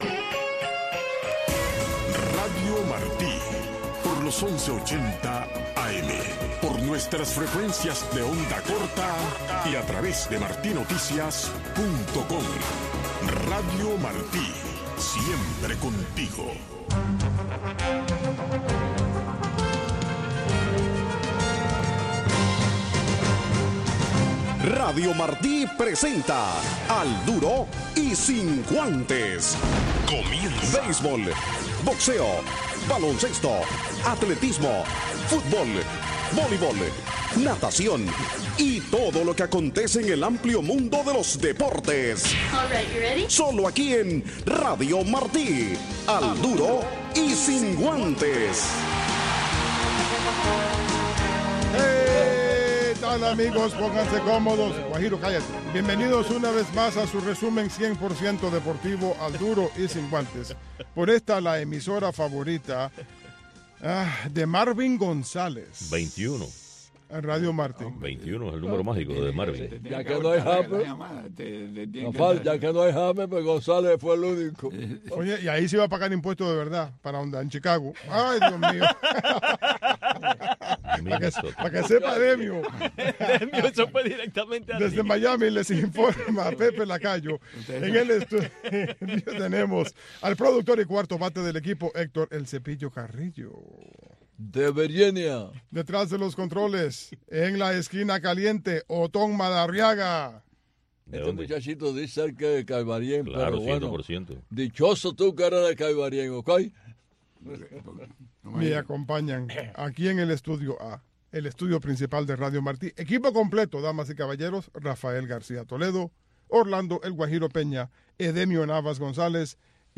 Noticiero Deportivo